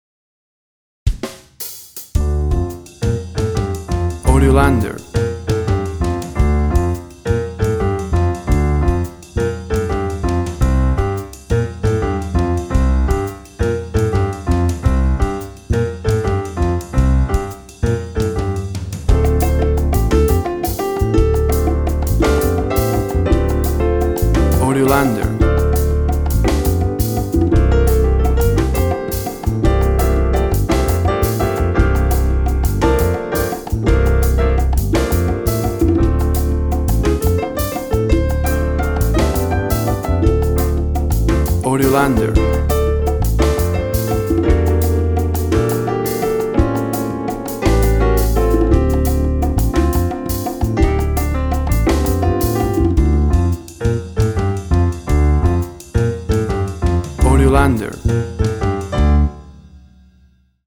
Afro Latin jazz on quartet band.
Tempo (BPM) 90